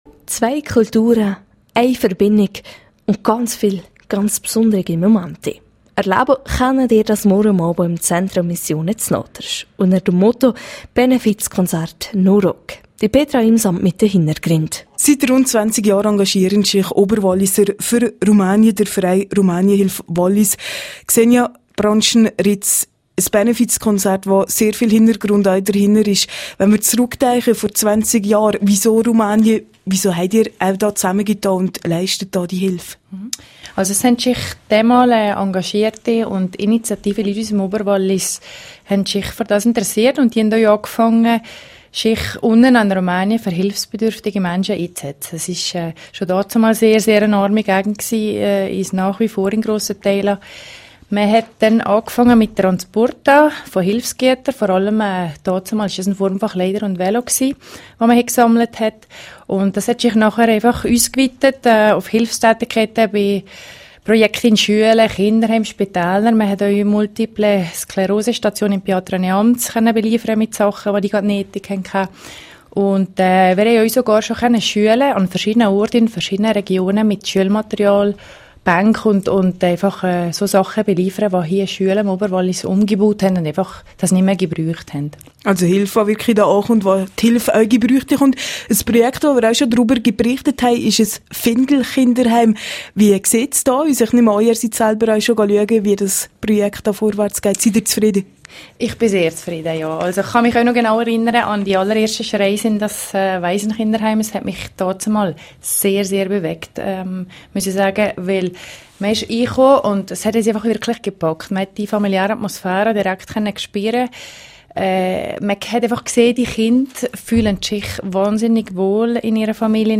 14184_News.mp3